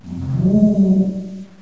GHOST.mp3